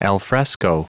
Prononciation du mot alfresco en anglais (fichier audio)
Prononciation du mot : alfresco